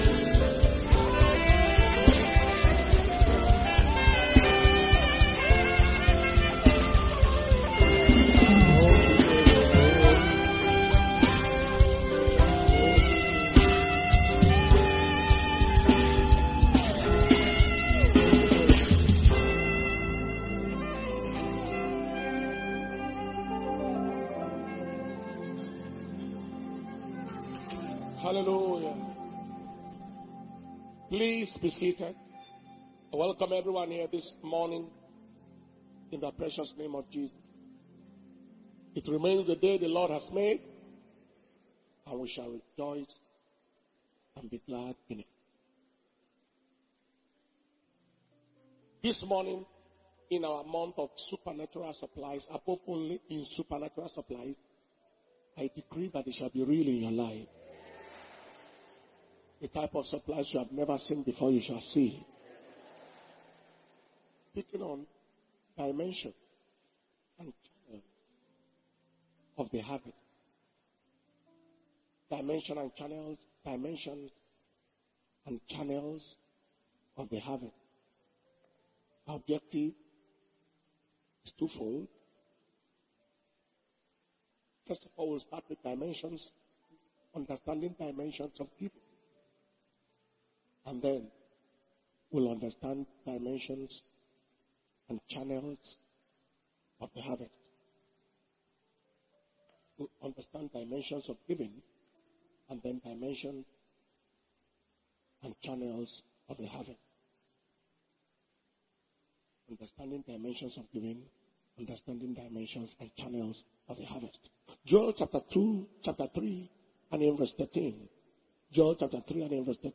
Dimensions And Channels Of The Harvest (1&2) mp3 By Dr Paul Enenche - February 2024 Testimonies And Thanksgiving Service